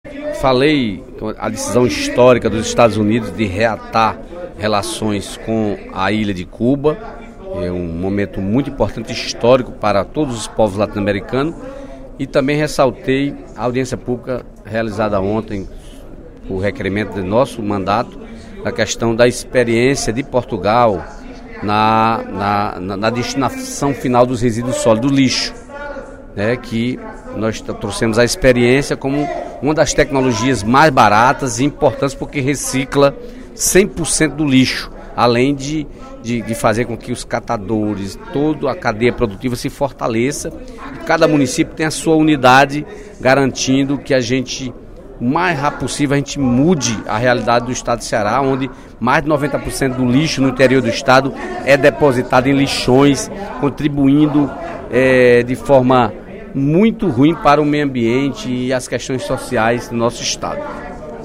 O deputado Dedé Teixeira (PT) destacou, no primeiro expediente da sessão plenária desta quinta-feira (18/12), a audiência pública promovida ontem pela Comissão de Meio Ambiente e Desenvolvimento da Assembleia Legislativa.